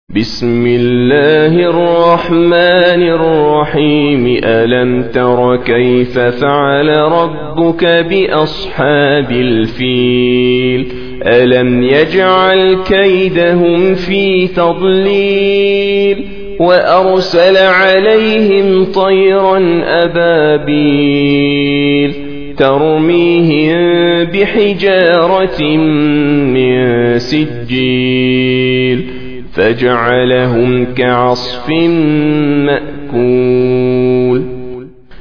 105. Surah Al-F�l سورة الفيل Audio Quran Tarteel Recitation
Surah Sequence تتابع السورة Download Surah حمّل السورة Reciting Murattalah Audio for 105.